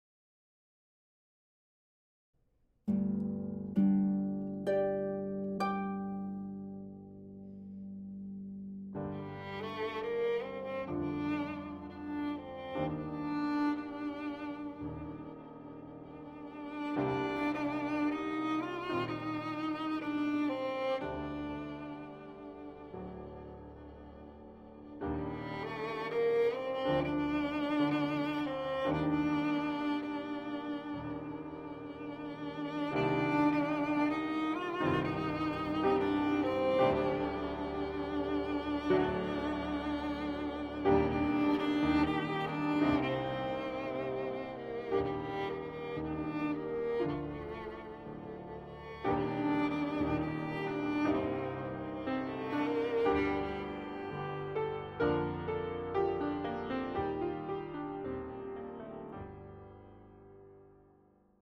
solo violin and piano
This arrangement is for solo violin and piano.